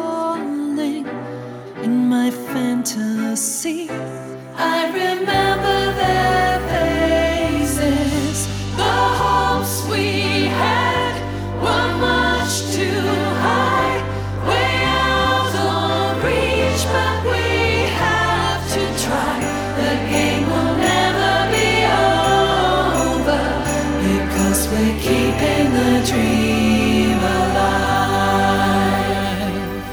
• Vocal Pop